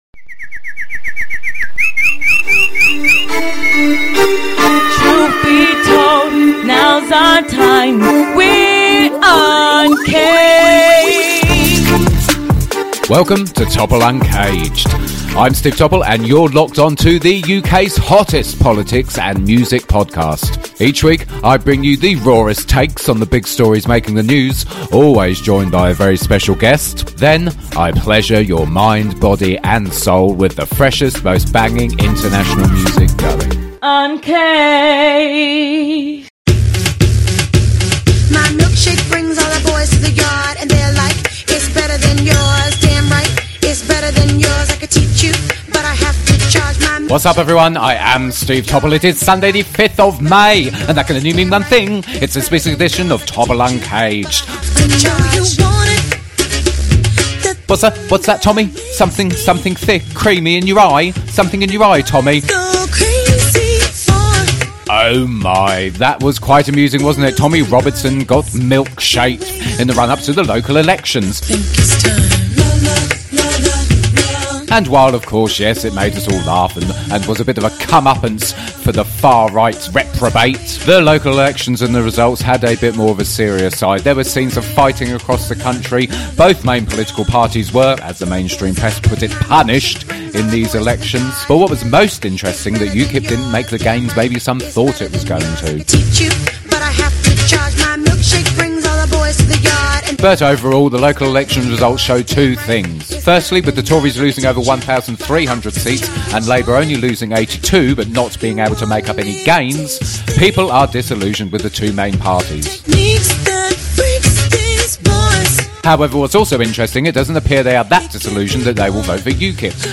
THIS PODCAST CONTAINS LANGUAGE AND CONTENT SOME PEOPLE MAY FIND OFFENSIVE.